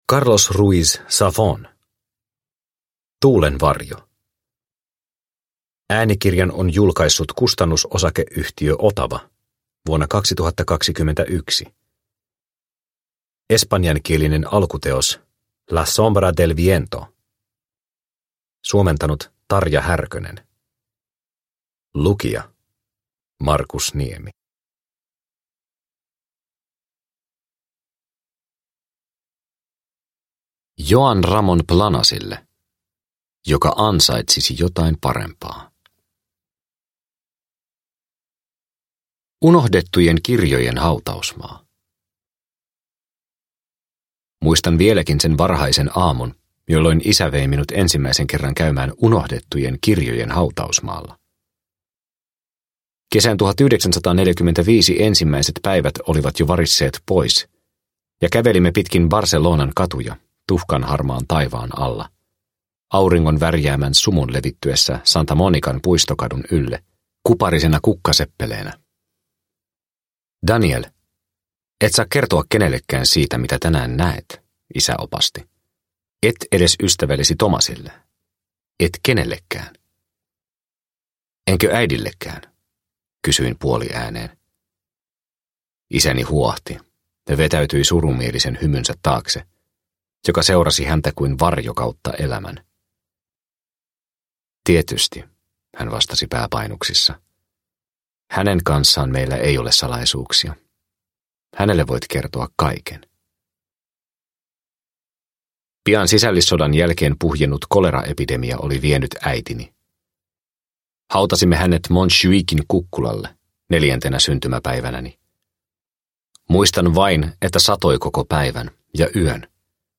Tuulen varjo – Ljudbok – Laddas ner